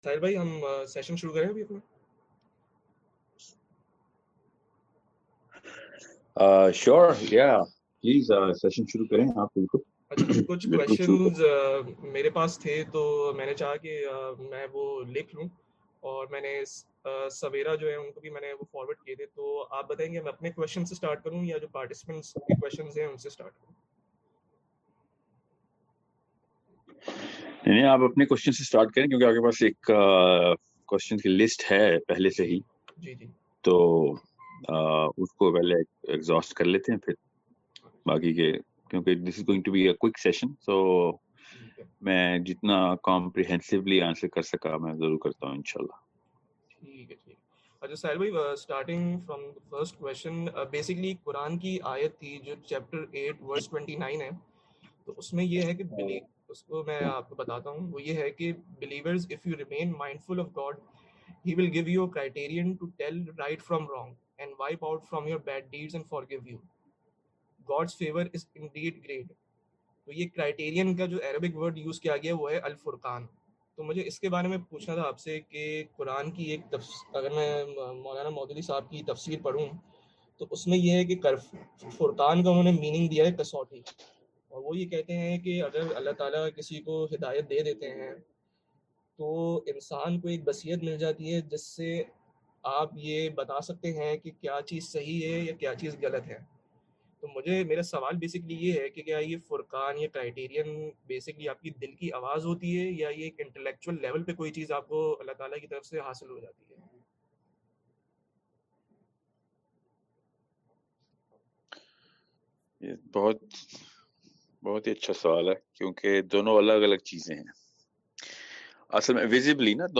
Building Thought Process of the Muslim youth ｜ QnA Series｜ PODCAST ｜ ZOOM LIVE SESSION.mp3